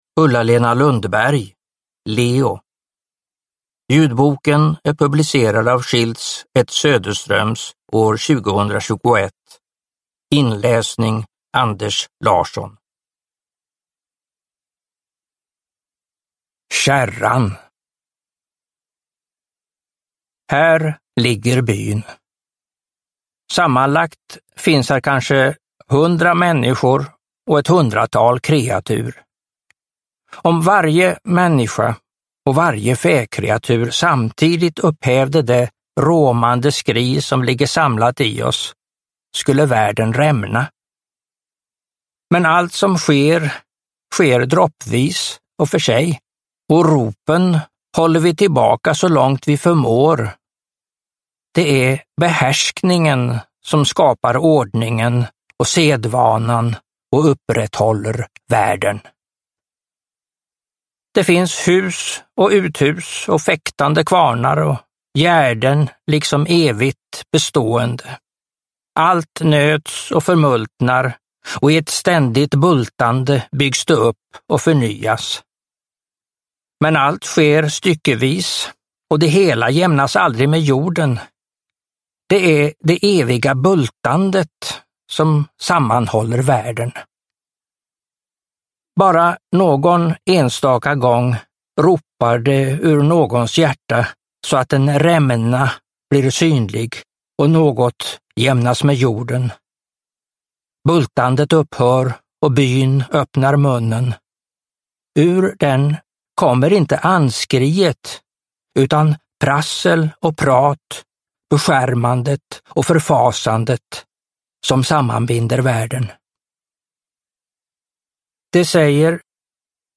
Leo – Ljudbok – Laddas ner